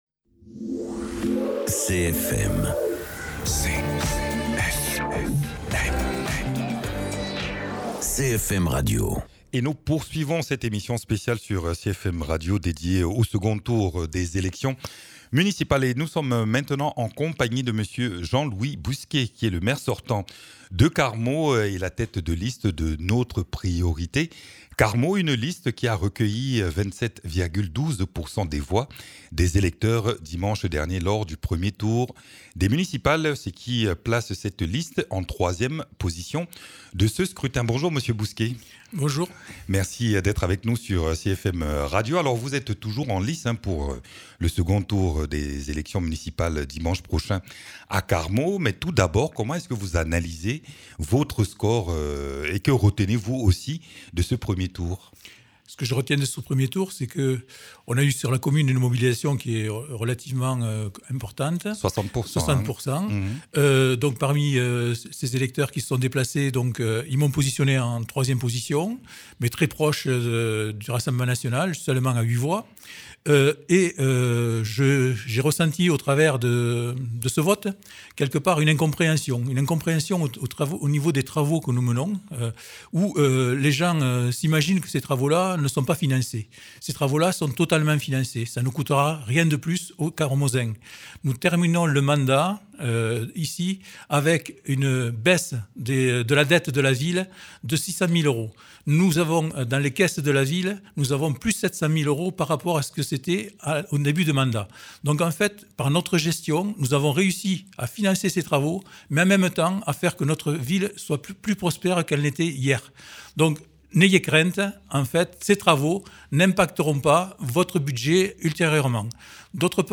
Invité(s) : Jean-Louis Bousquet pour la liste "Notre priorité Carmaux".